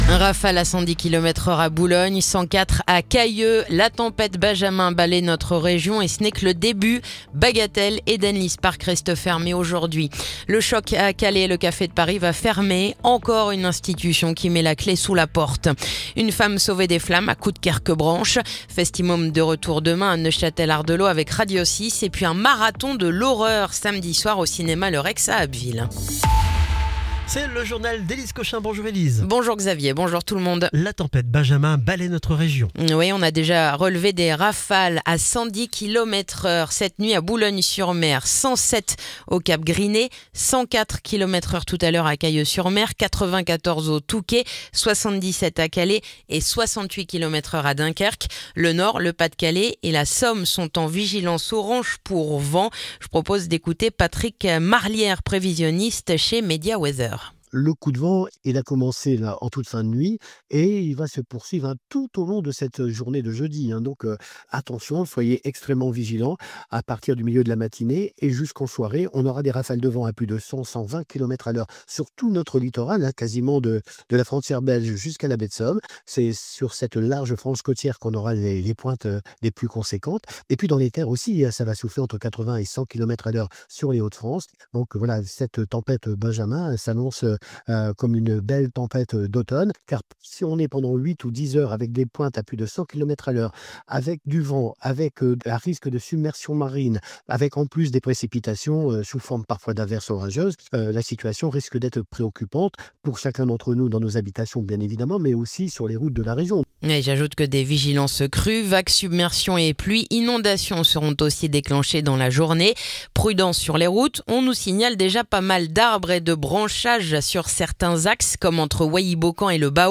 Le journal du jeudi 23 octobre